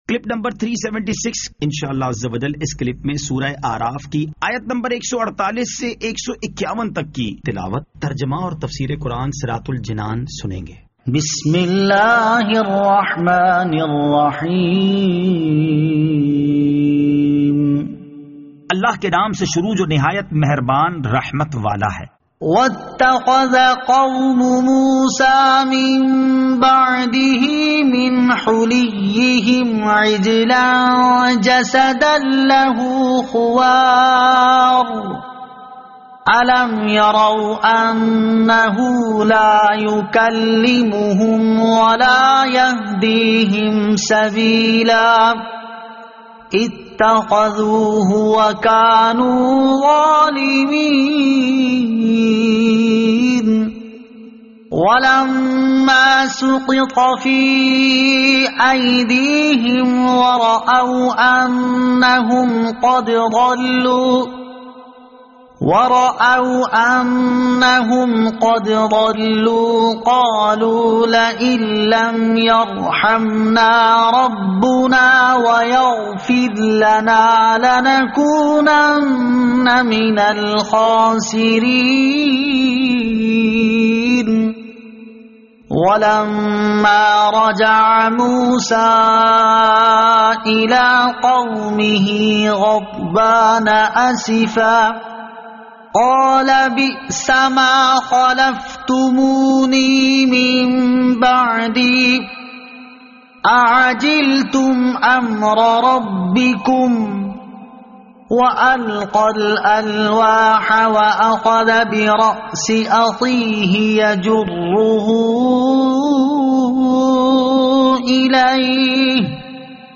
Surah Al-A'raf Ayat 148 To 151 Tilawat , Tarjama , Tafseer